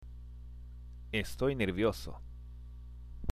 （エストイ　ネルビオソ）